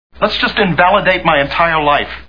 Six Feet Under TV Show Sound Bites